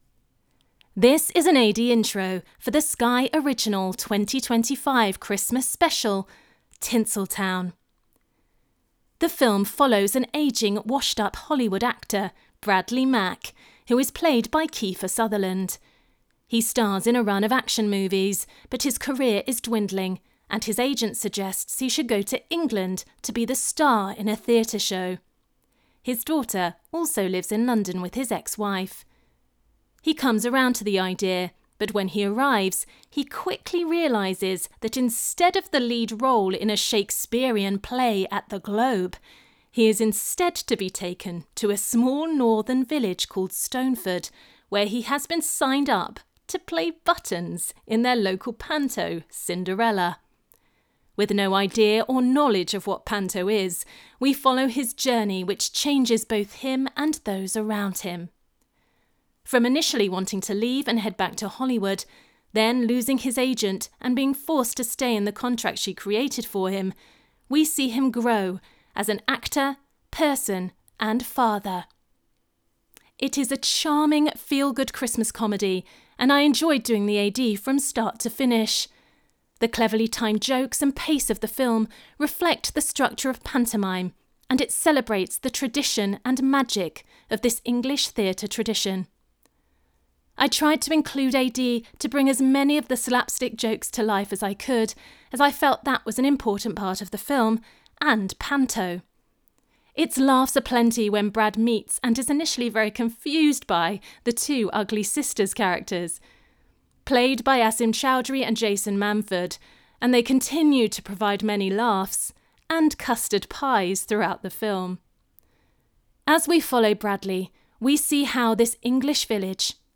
Tinsel Town - AD Introduction